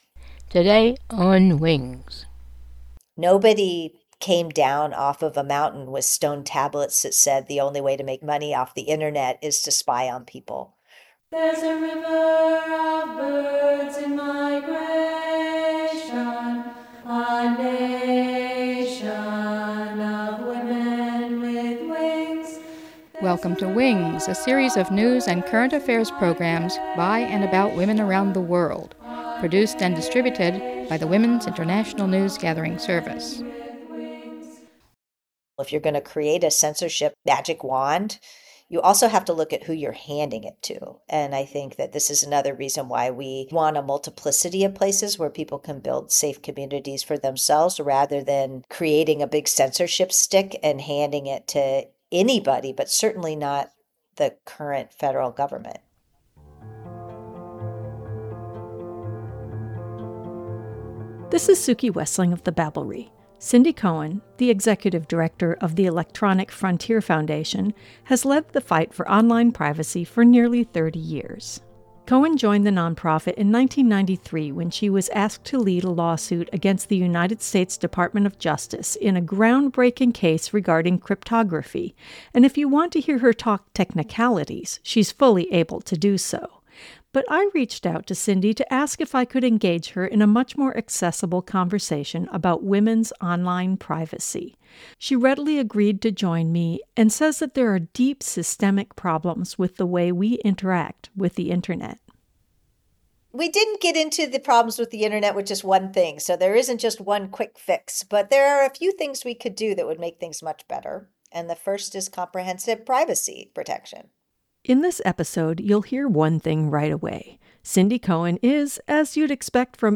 Guest: Cindy Cohn, Executive Director of the Electronic Frontier Foundation and author of Privacy’s Defender: My Thirty-Year Fight Against Digital Surveillance